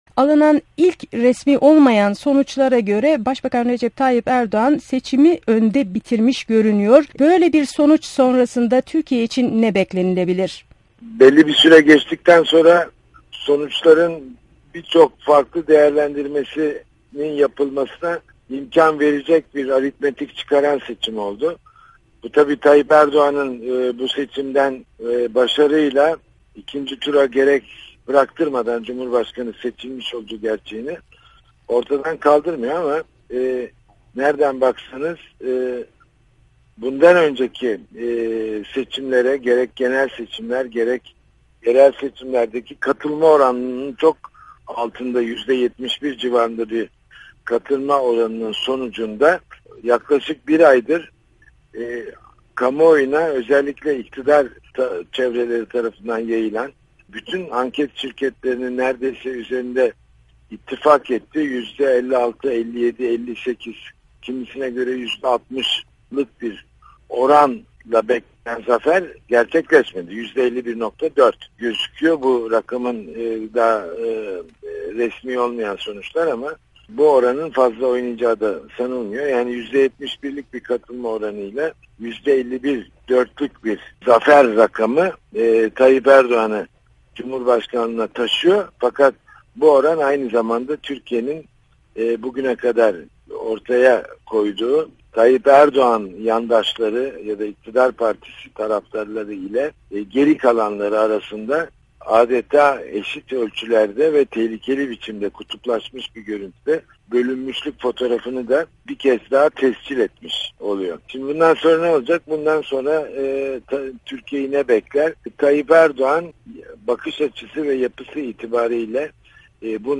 Cengiz Çandar ile Söyleşi